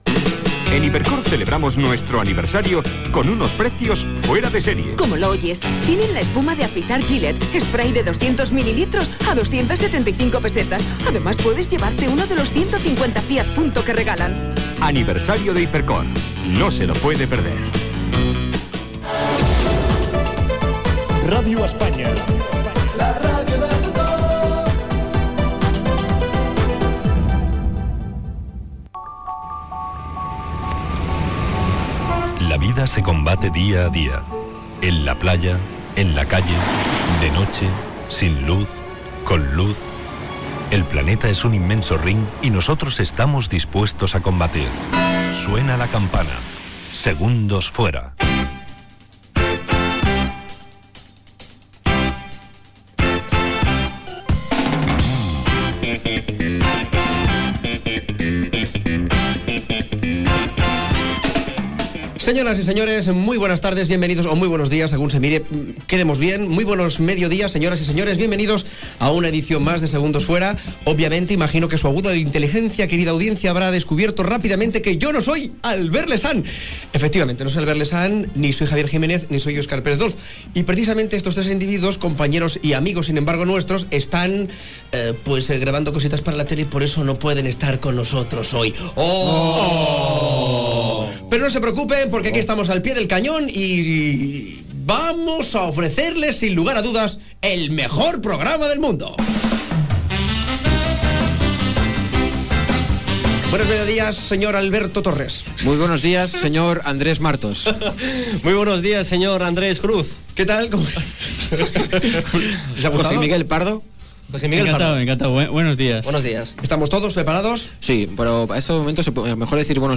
Publicitat, indicatiu de l'emissora, careta, presentació de l'equip del programa, notícia sobre la confiança dels espanyols en els polítics i comentaris sobre el tema, pregunta a l'audiència.
Entreteniment
FM